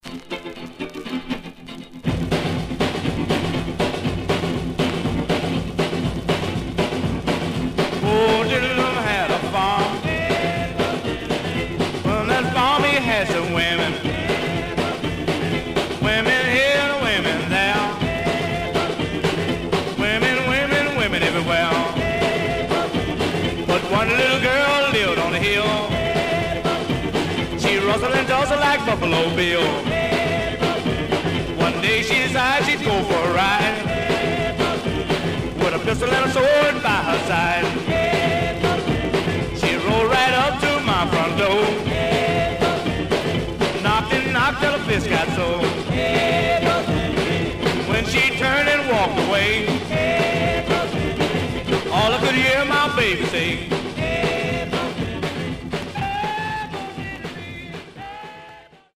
Some surface noise/wear
Mono
Rythm and Blues Condition